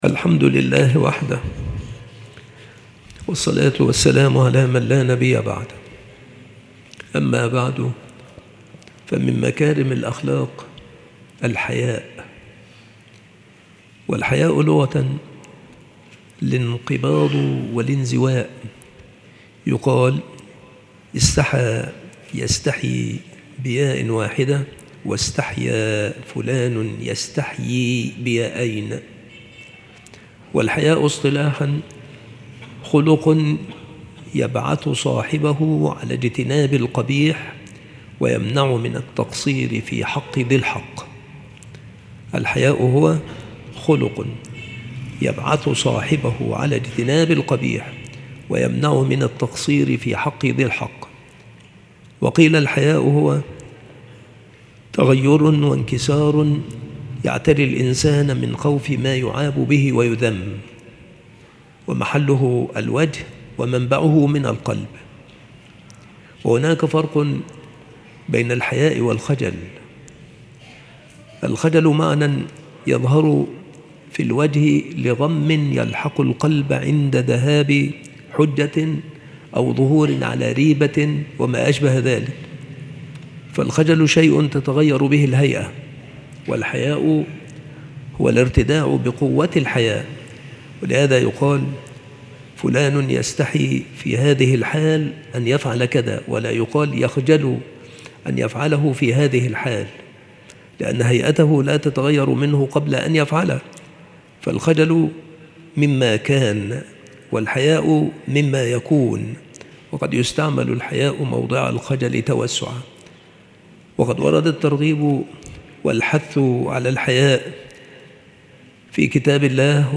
مواعظ وتذكير
مكان إلقاء هذه المحاضرة بالمسجد الشرقي - سبك الأحد - أشمون - محافظة المنوفية - مصر